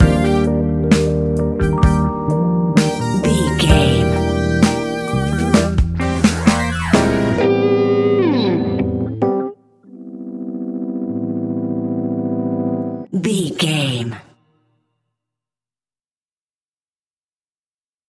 Ionian/Major
E♭
hip hop
instrumentals